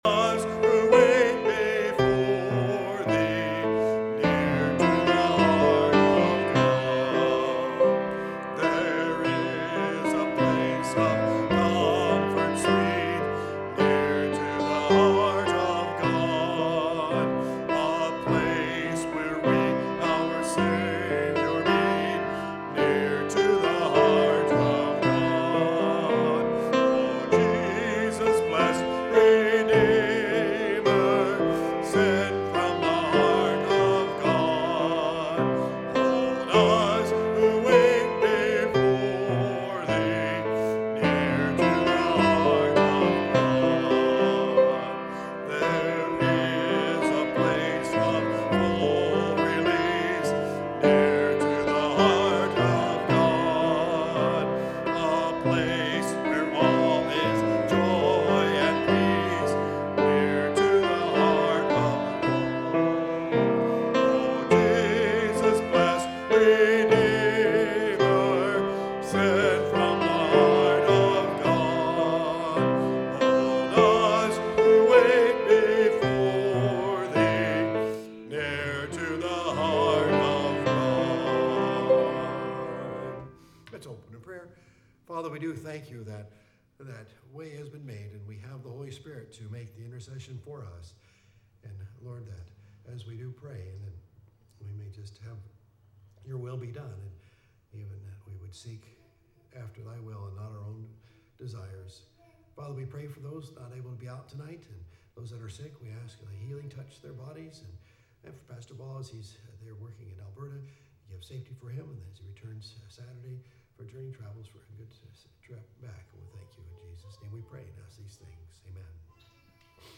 Prayer Meeting